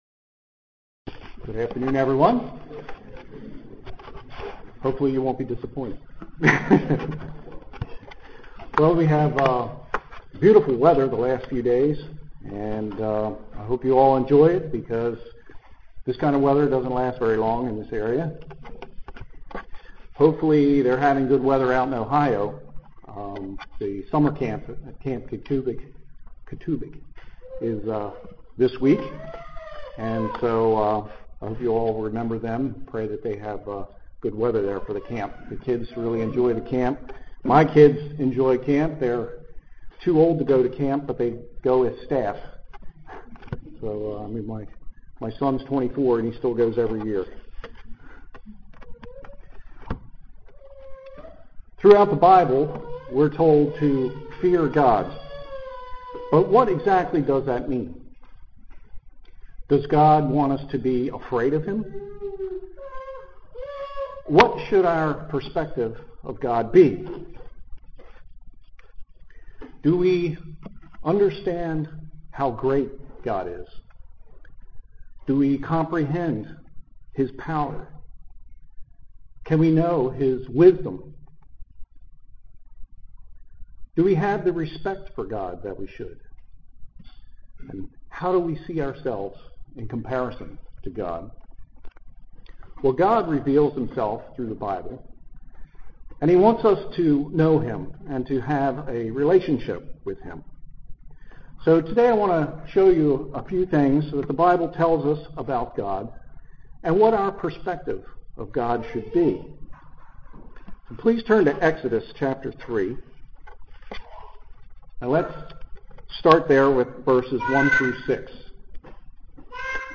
Given in Columbia, MD
UCG Sermon Studying the bible?